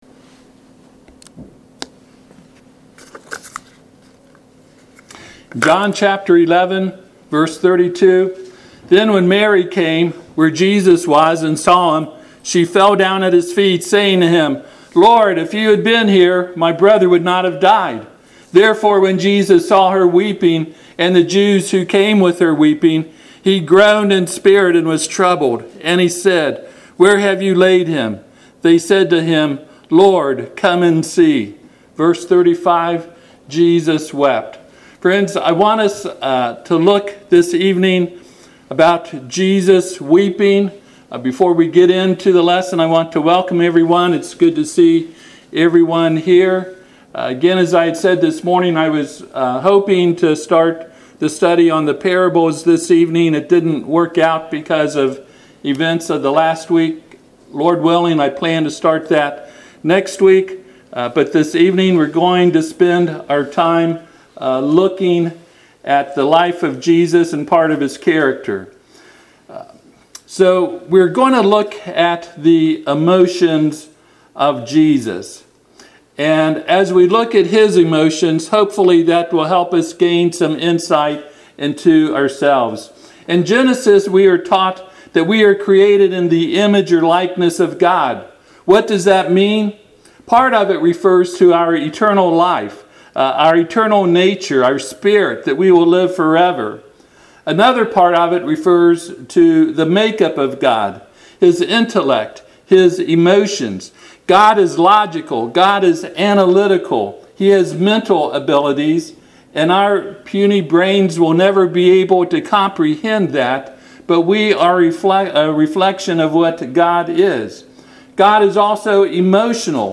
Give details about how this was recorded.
John 11:35-36 Service Type: Sunday PM https